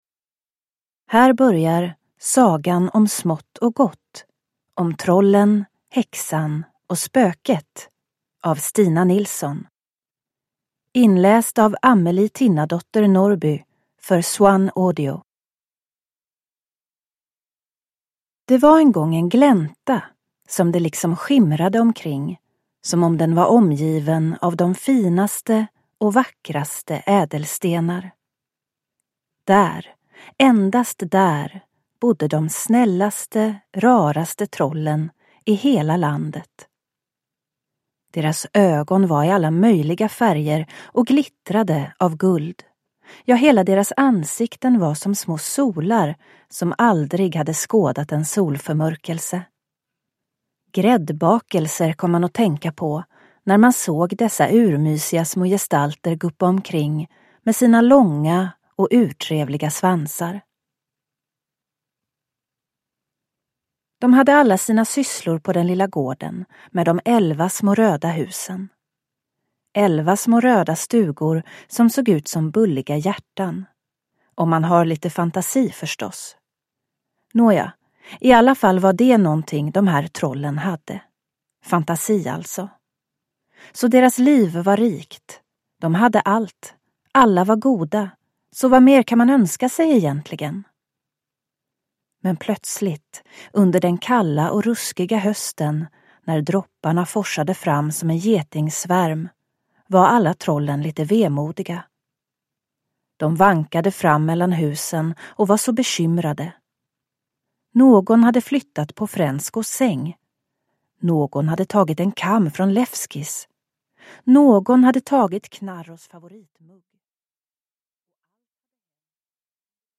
Sagan om Smått och Gott - om trollen, häxan och spöket – Ljudbok – Laddas ner